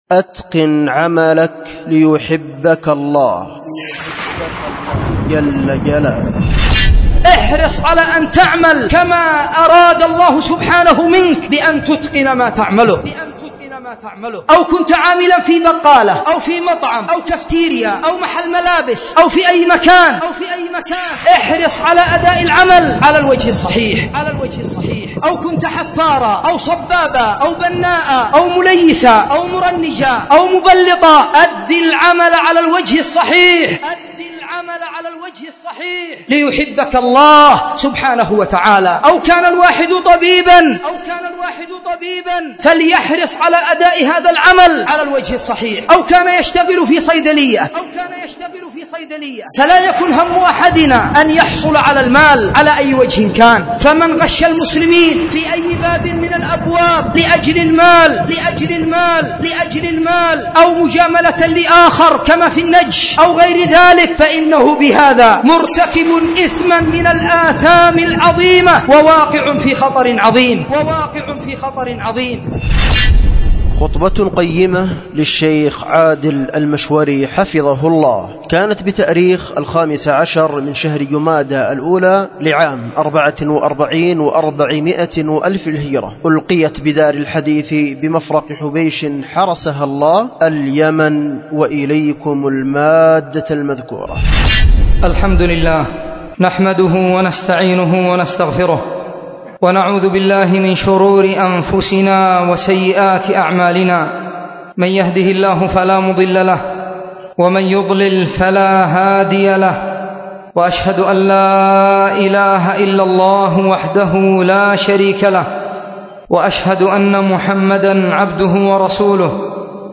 خطبة
ألقيت بدار الحديث بمفرق حبيش